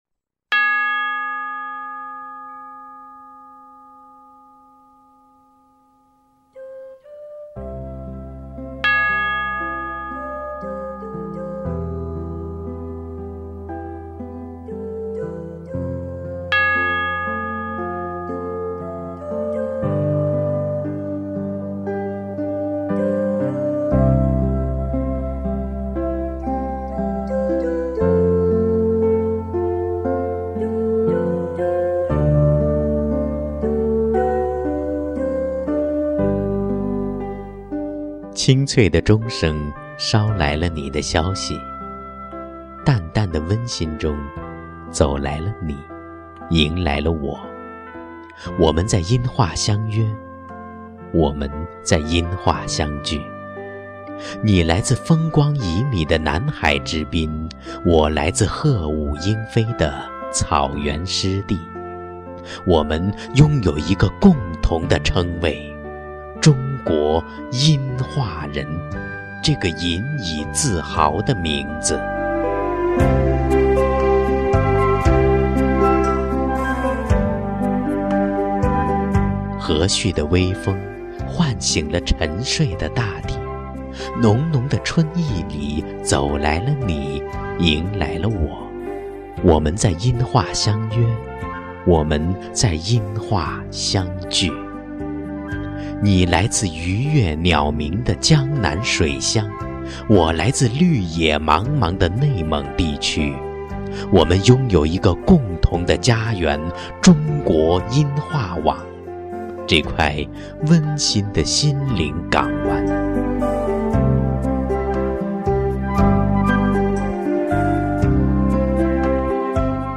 配乐朗诵